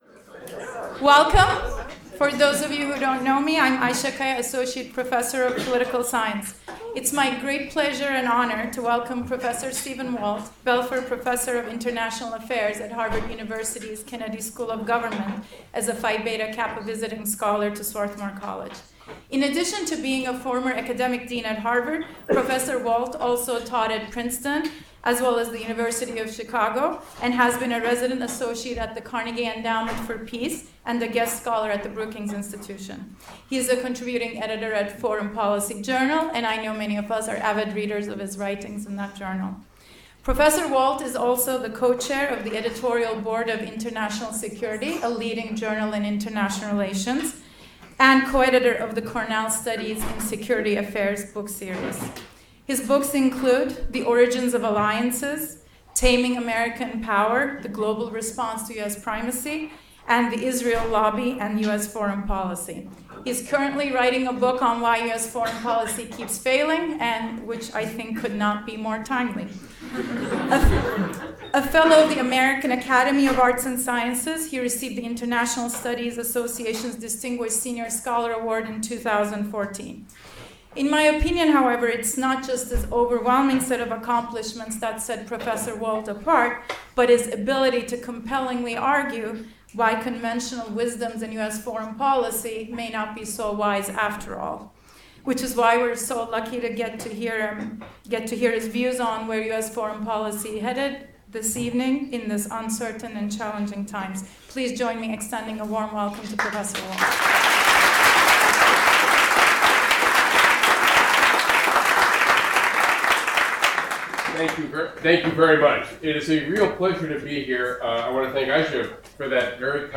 Stephen Walt, Robert and Renee Belfer Professor of International Affairs Harvard University, delivers the Phi Beta Kappa Visiting Scholar Lecture.